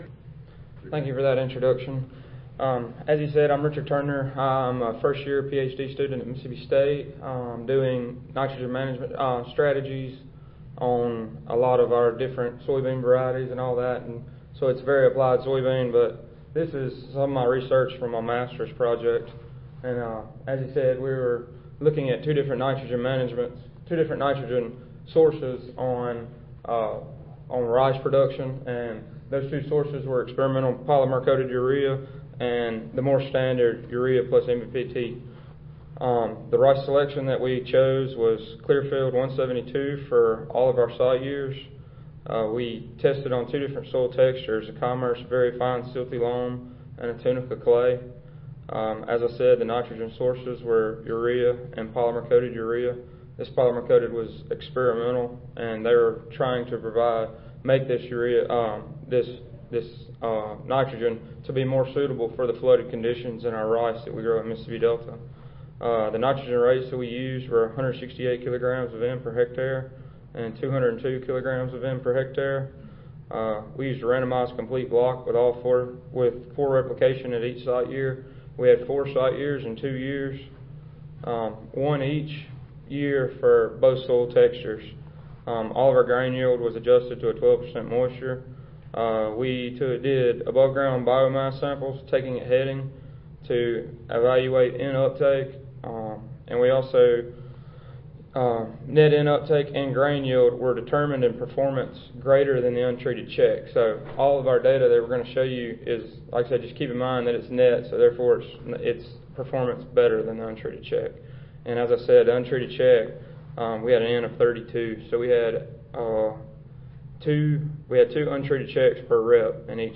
2017 Annual Meeting | Oct. 22-25 | Tampa, FL
Oral Session
Audio File Recorded Presentation